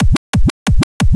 Dj Bass Drum Scratch Sound Effect Free Download
Dj Bass Drum Scratch